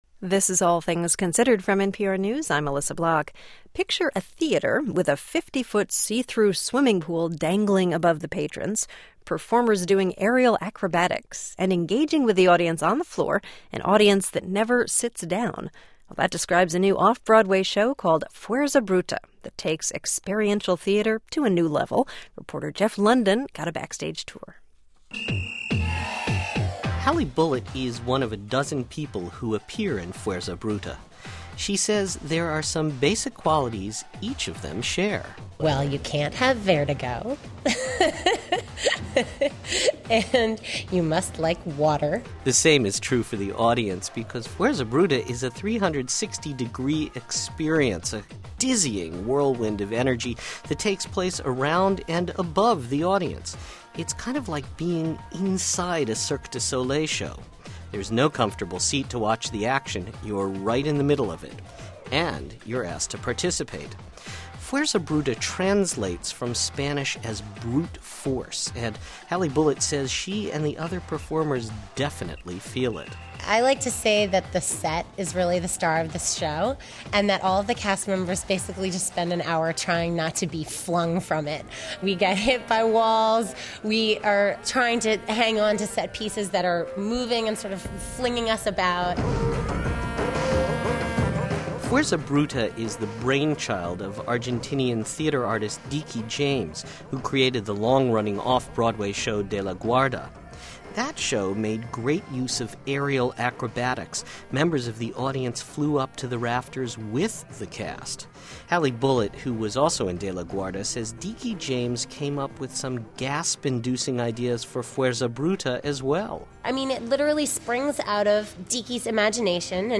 Interview with NPR’s All Things Considered